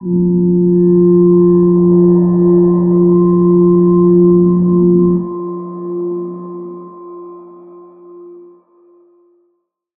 G_Crystal-F4-mf.wav